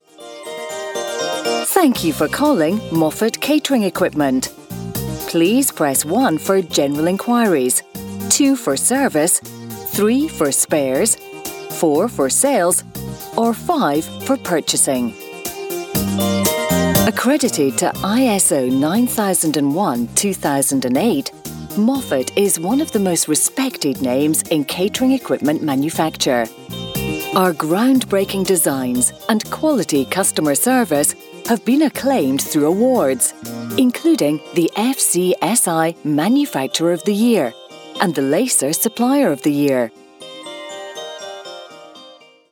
Scottish Female Voice Overs for On Hold & IVR
Accent: Soft Scottish
Tone / Style: Warm and friendly